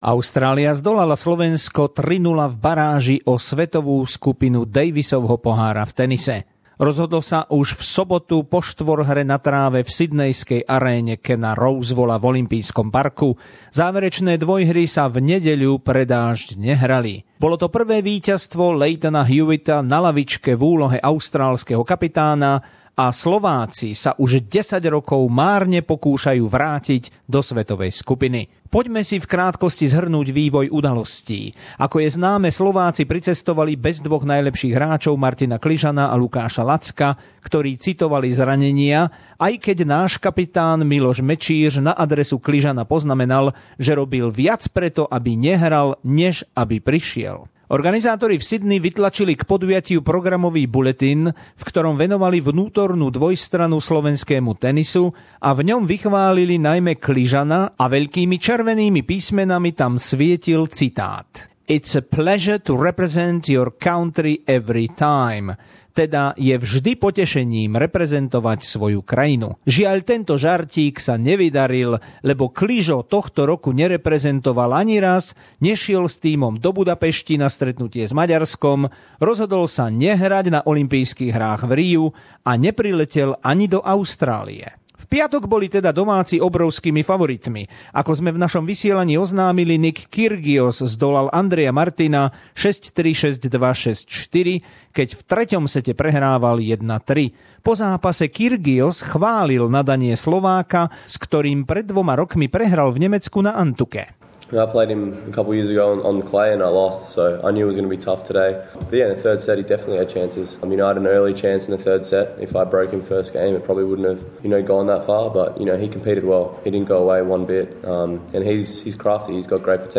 V baráži o účasť vo svetovej skupine tenisového Davisovho pohára (16.-18. septembra) sa v Sydney stretli tímy Austrálie a Slovenska a Austrália si jednoznačným výsledkom 0:3 potvrdila pozíciu favorita. Reportáž priamo zo Sydney